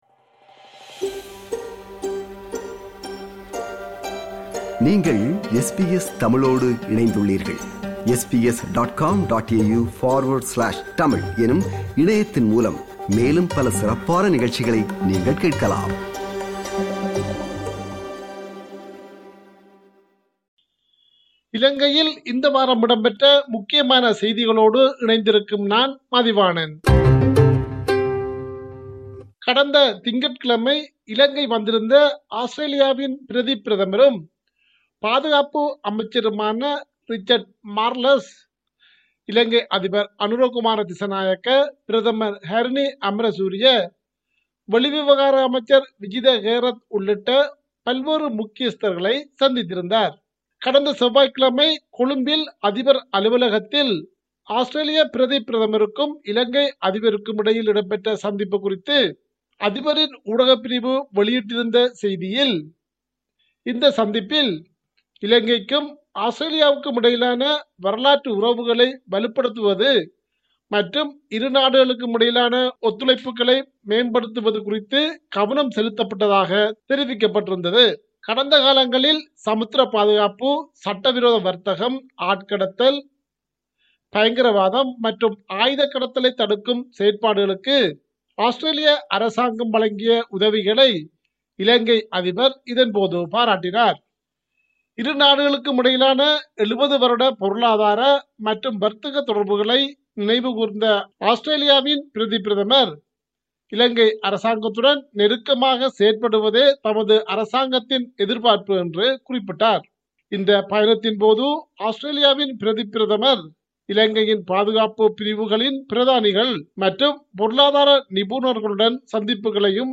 இலங்கையின் இந்த வார முக்கிய செய்திகள்